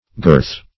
girth - definition of girth - synonyms, pronunciation, spelling from Free Dictionary